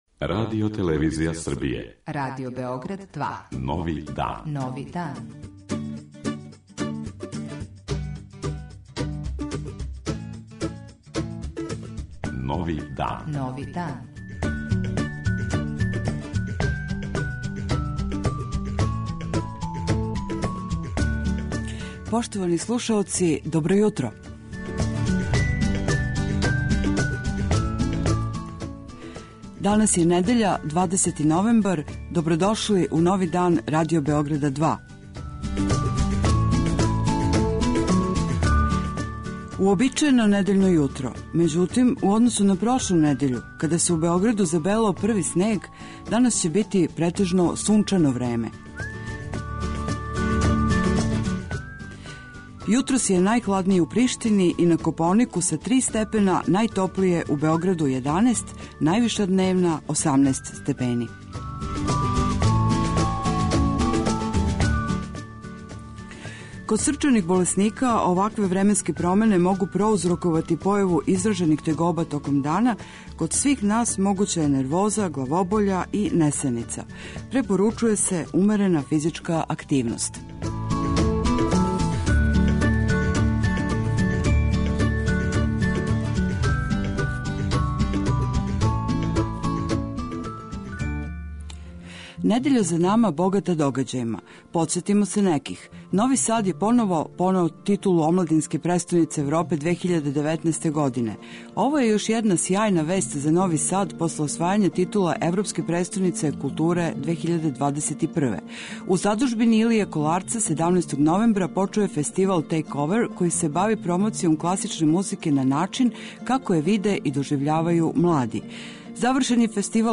У Новом дану, овога 20. новембра, слушаћемо о чему се говорило на Симпозијуму офталмолога у Нишу, листаћемо старе новине и видети шта се дешавало у култури пре сто година, сазнаћемо шта се у наредној недељи догађа у култури Врања и околине, шта је занимљиво у Ваљеву, купују ли се књиге у Јагодини, куда са Теслом из Ужица, али и шта ће се дешавати у Београду. Из Зајечара ћемо чути причу о Шанку и Бонки, зајечарским Ромеу и Јулији, али и стари аутохтони језик којим се говори у селу Велики Извор код Зајечара.
И ове недеље - много добре музике